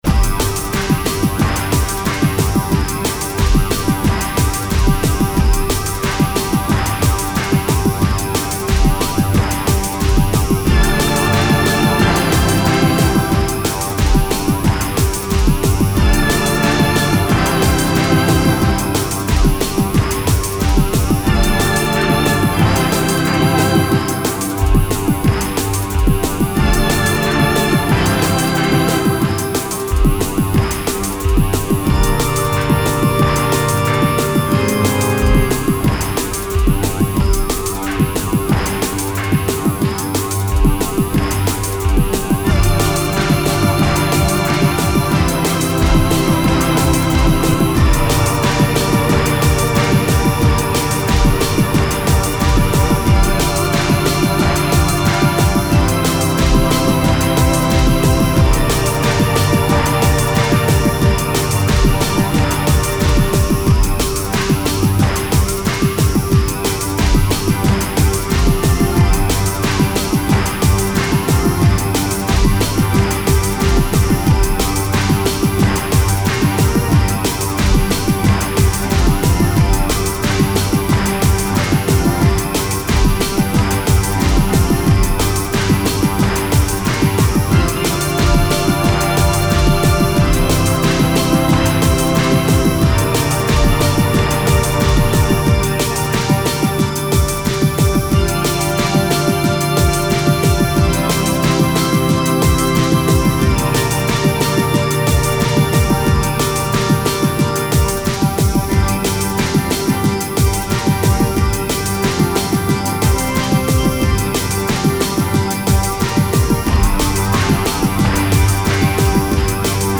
Tempo: 90 bpm / Datum: 21.08.2017